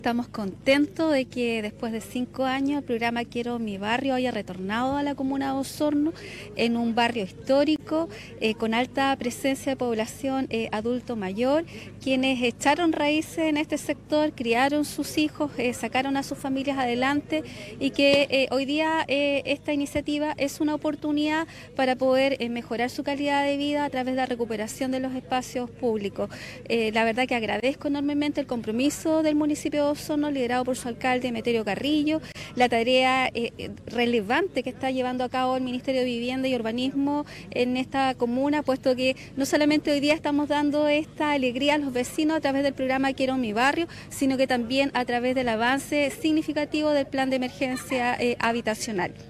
El sábado recién pasado, se realizó el hito inaugural del Programa de Recuperación de Barrios en la Población García Hurtado de Mendoza, donde se ejecuta el Programa Quiero Mi Barrio del Ministerio de Vivienda y Urbanismo, en coordinación con la Municipalidad de Osorno.
Por otra parte, la Delegada Presidencial Provincial Claudia Pailalef, destacó el retorno del Programa Quiero Mi Barrio a la comuna de Osorno, principalmente en un sector donde hay un gran número de personas mayores que requieren avanzar en mejoras.